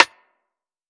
ATR Snare (30).wav